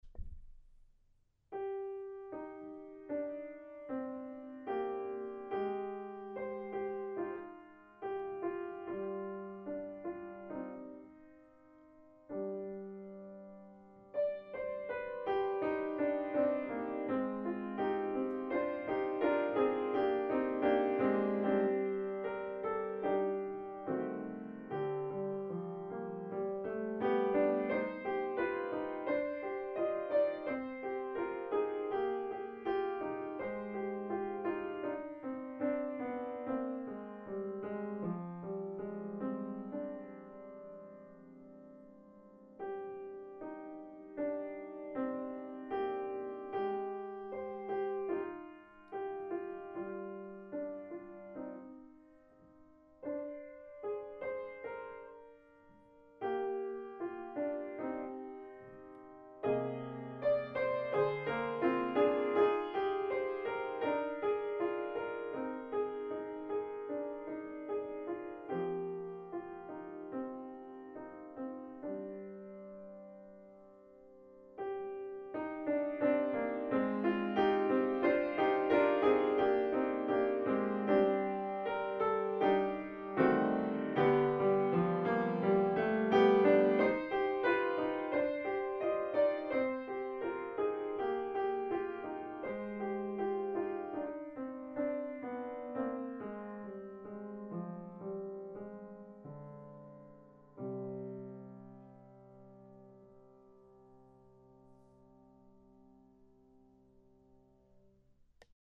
It is a beautiful and familiar tune, known as Bangor (an old Scottish tune of such popularity it was even mentioned in a Robbie Burns poem!).